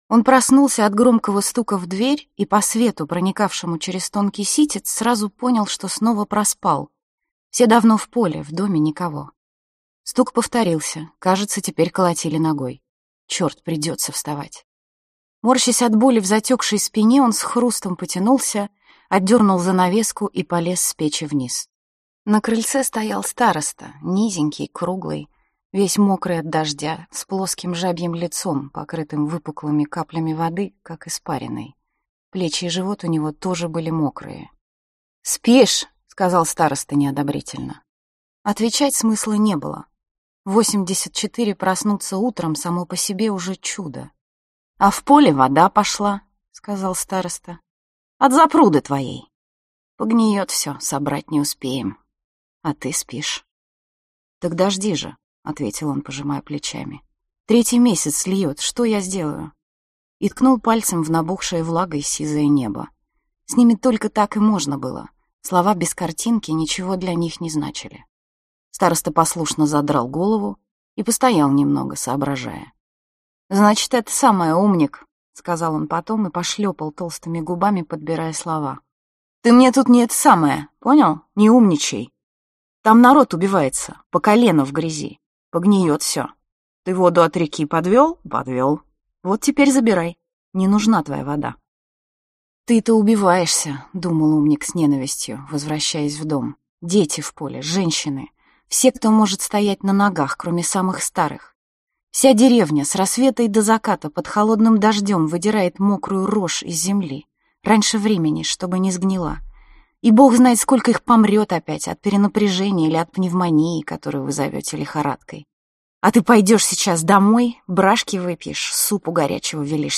Аудиокнига 2068 | Библиотека аудиокниг
Aудиокнига 2068 Автор Яна Вагнер Читает аудиокнигу Яна Вагнер.